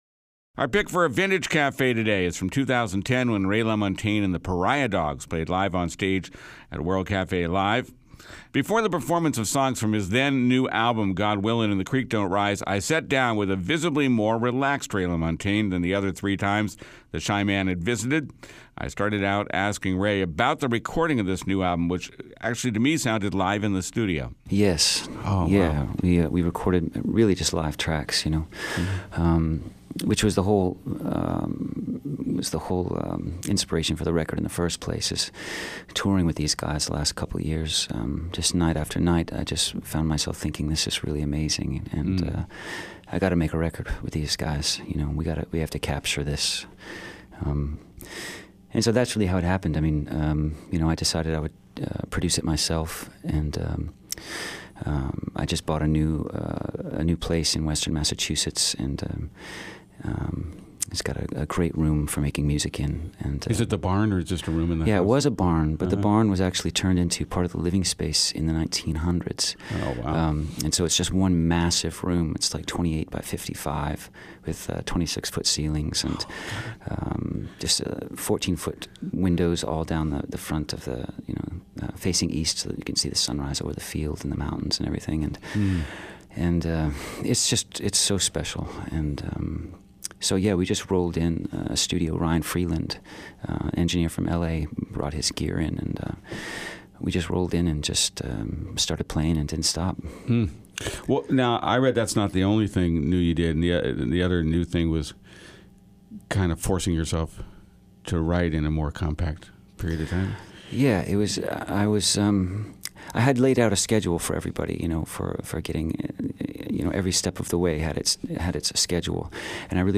old-school Americana